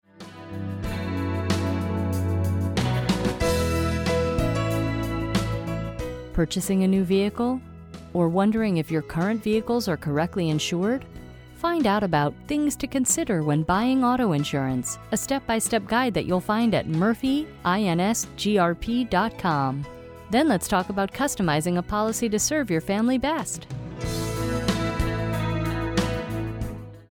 A well planned on hold script recorded with a professional voice over makes every call more interesting, and can help you sound more professional and enhance your professional image.
Here are samples of actual Informer Messages